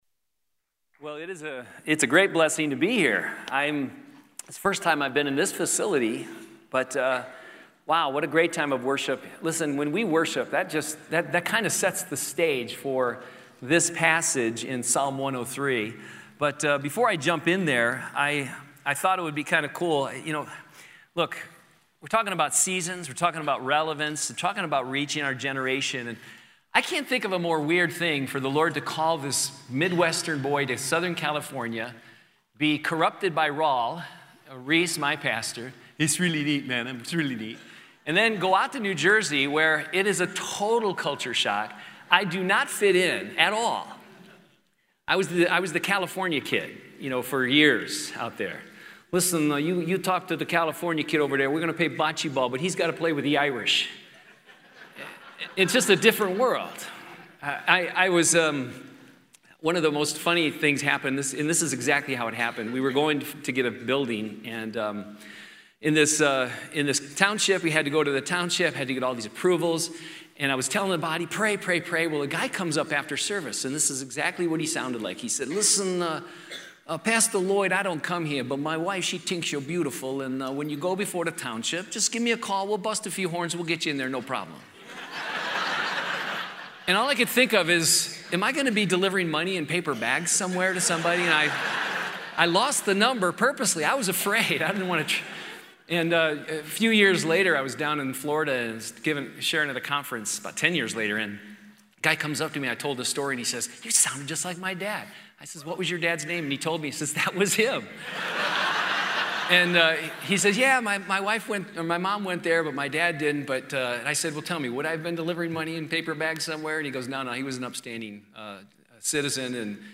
2017 SW Pastors and Leaders Conference